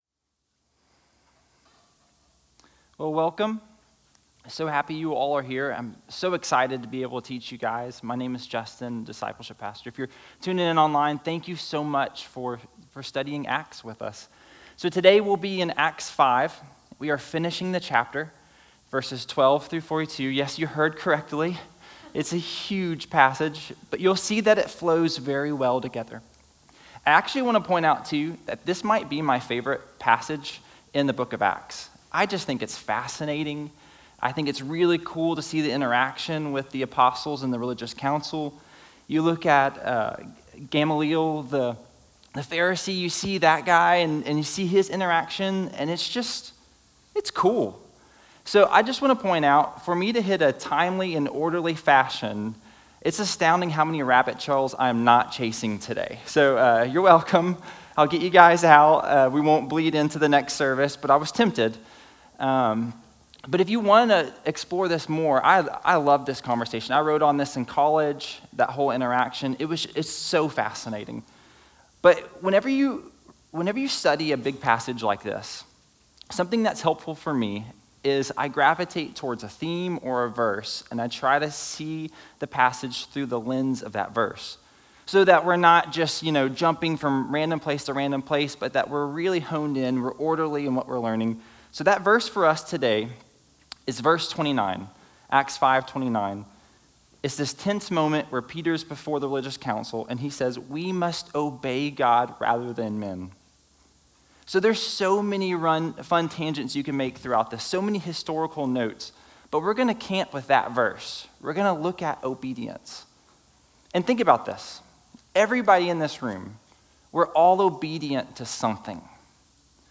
Sermon Audio…